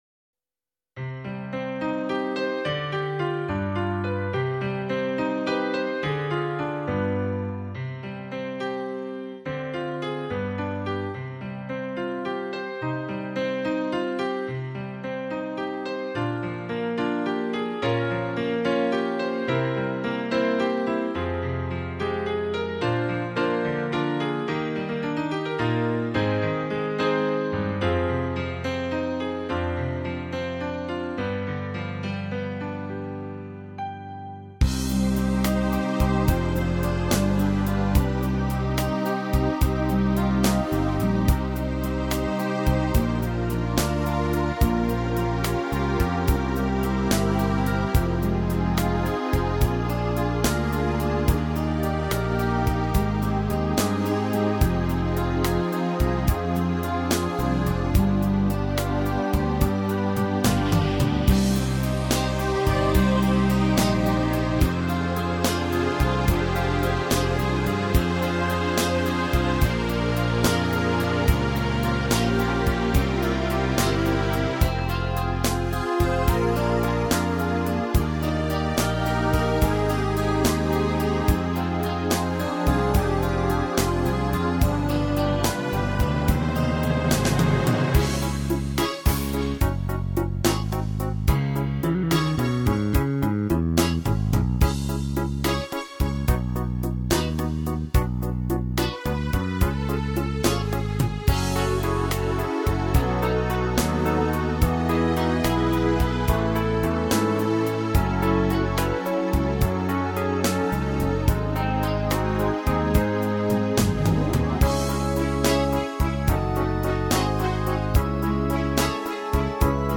Пойте караоке